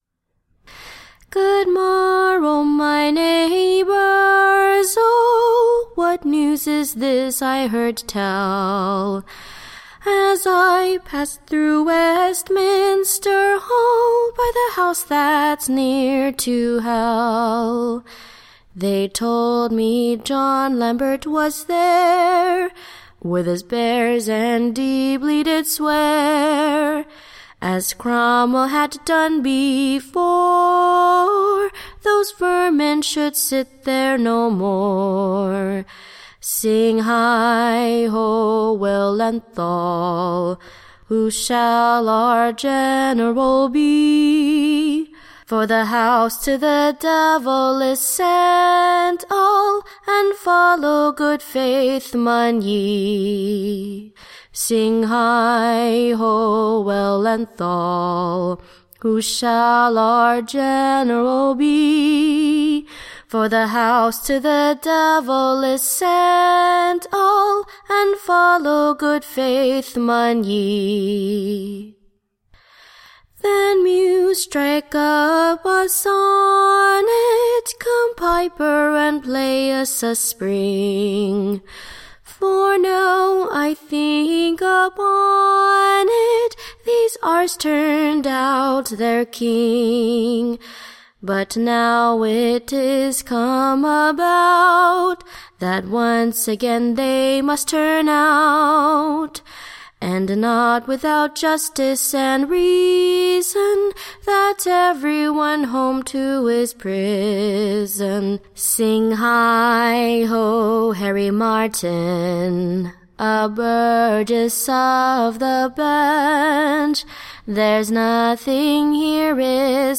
Recording Information Ballad Title A proper new Ballad on the Old Parliament. Or, the second part of Knave out of Doores.